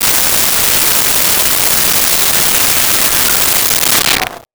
Alien Wierdness Descending 02
Alien Wierdness Descending 02.wav